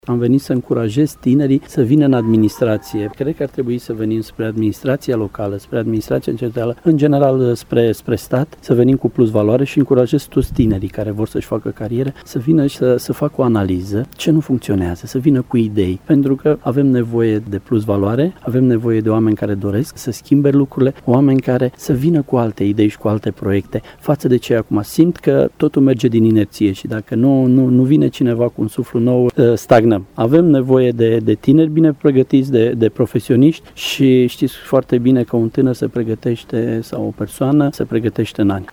Ministrul Tineretului şi Sportului, Marius Dunca a declarat azi, la Sfântu Gheorghe, că încurajează tinerii să facă practică şi să lucreze în instituţiile de stat.